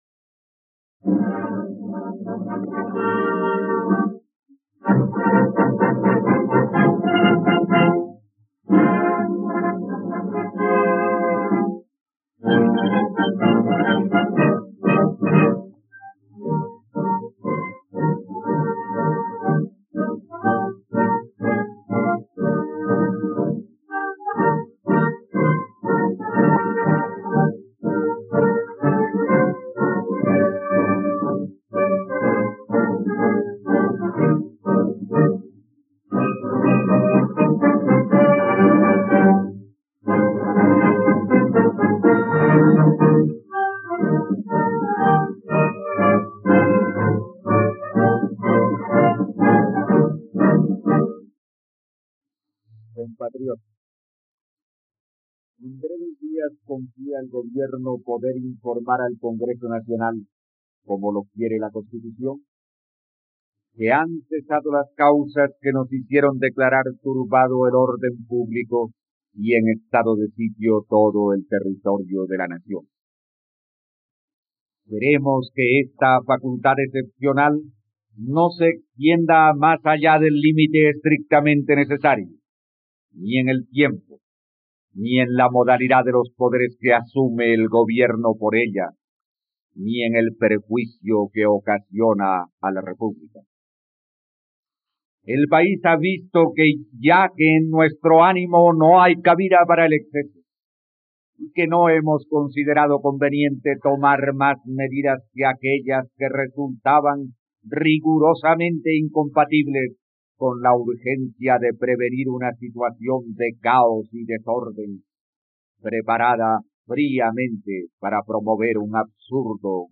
..Escucha ahora el discurso de Alberto Lleras Camargo sobre la subversión del general Gustavo Rojas Pinilla, el 10 de diciembre de 1958, en RTVCPlay.